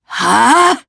Demia-Vox_Casting3_jp.wav